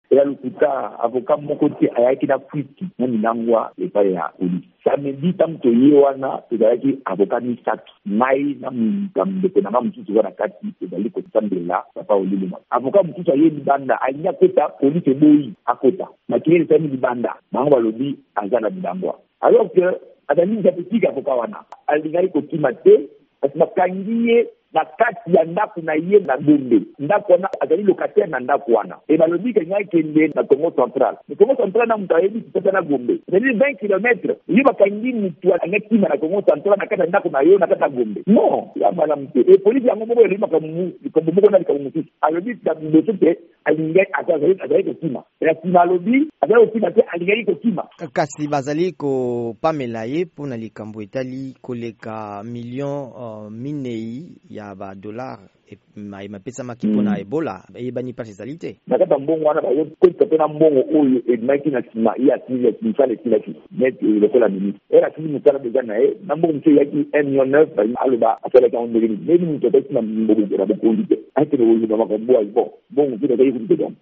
VOA Lingala ebangani na singa moko na basambela baye